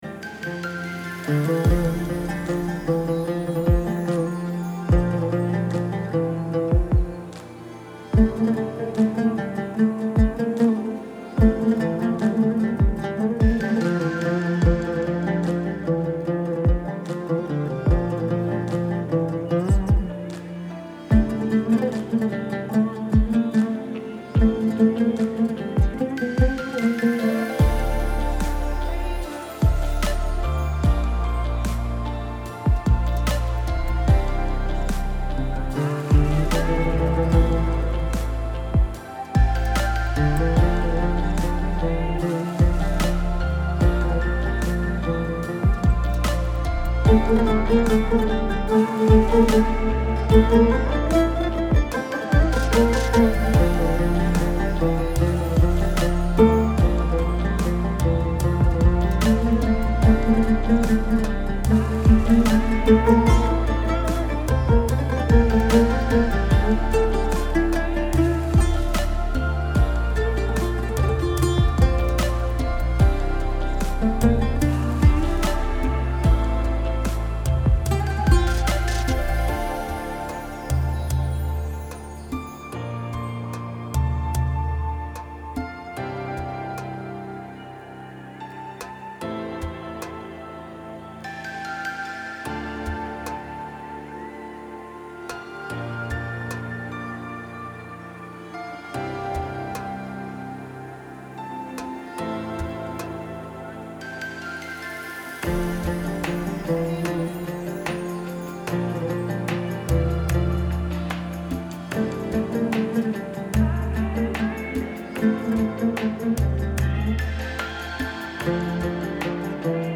Audio Branding Elements
• Music Theme OUD 00:00